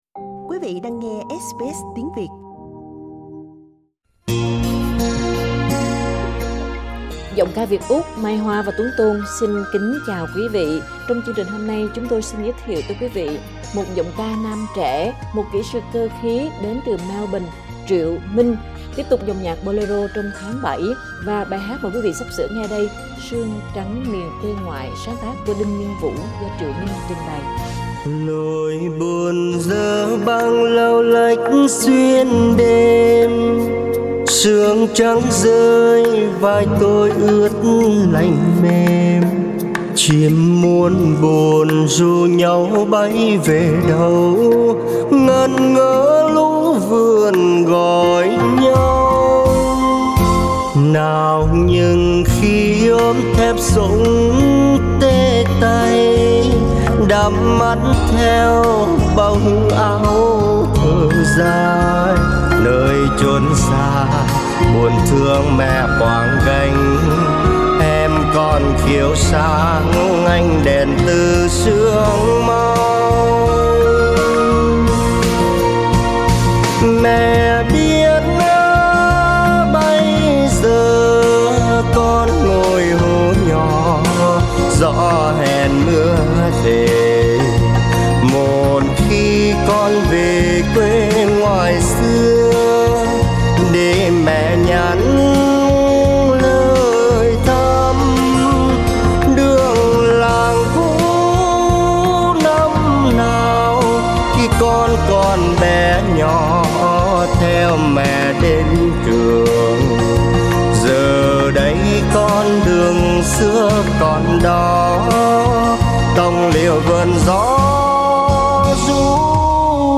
Cái hay của những giọng ca không bị gò qua trường lớp, mỗi người một phong cách, chất giọng riêng, không ai lẫn với ai.